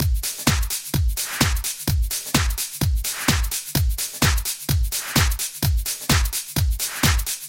Here is a sample of the type of beat you could expect to end up with at the end of this series of tips: